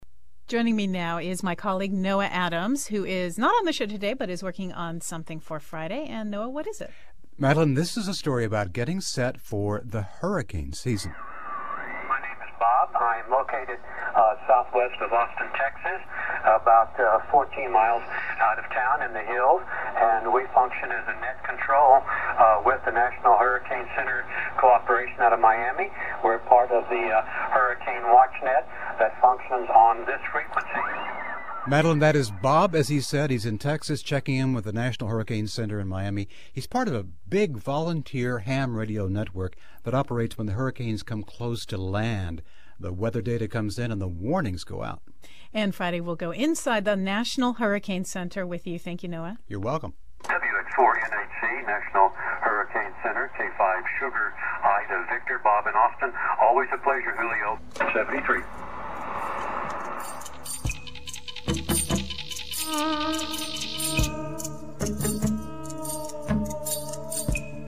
> The program promo clip MP3 audio: